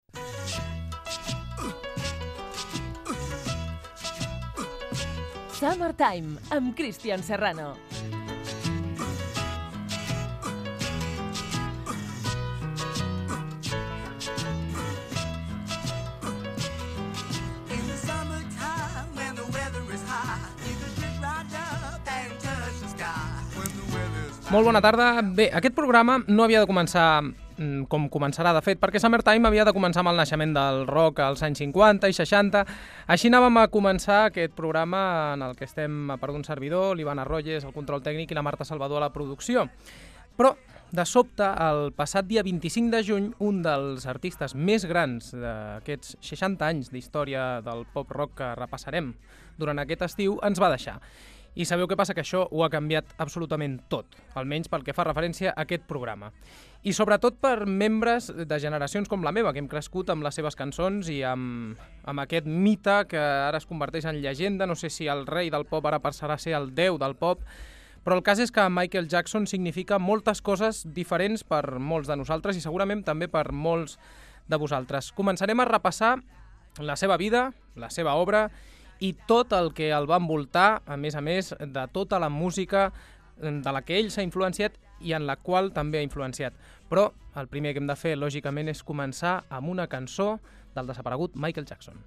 Indicatiu del programa i inici. Equip i record a Michael Jackson
Musical